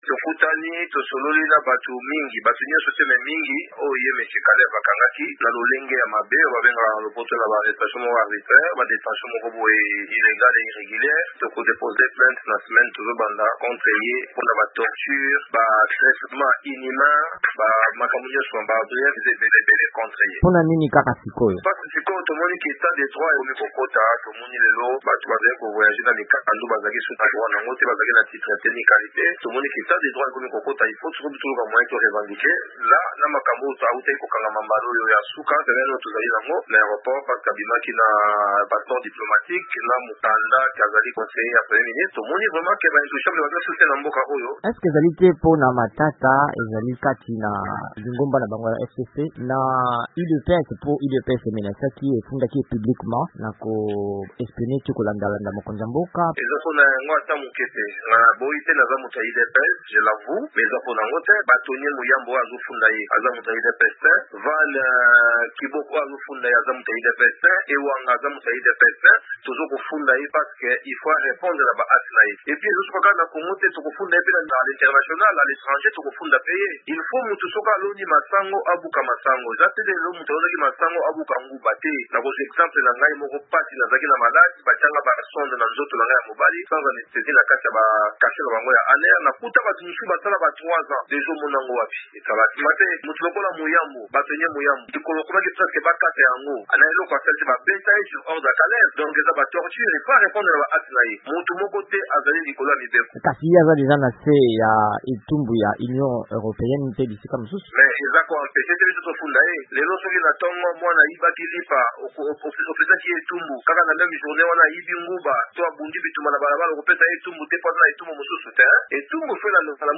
Gerard Gecoco Muluma, député ya Kinshasa, azali moko na bato baye bazali kofunda na bazuzi Kalev Mutond mokambi ya kala ya ANR. VOA Lingala epesaki malabo na Gecoco Mulumba.